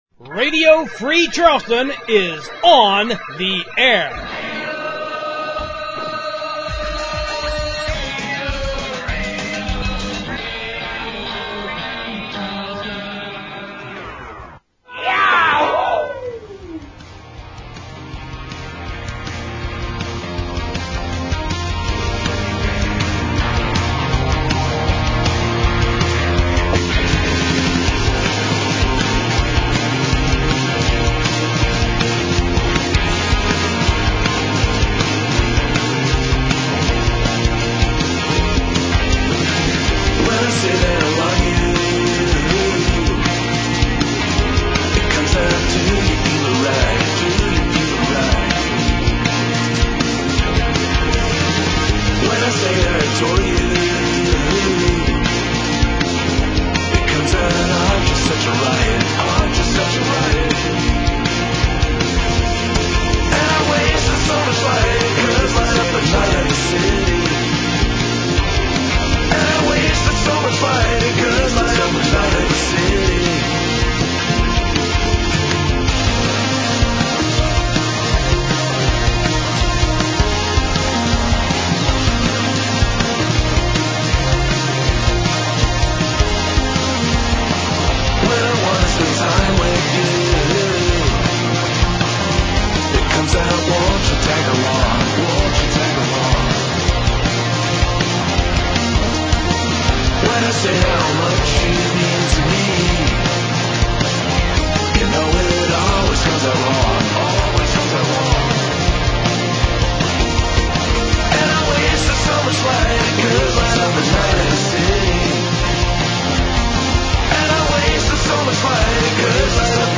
As you can see, it’s free-form radio at its most freeformy.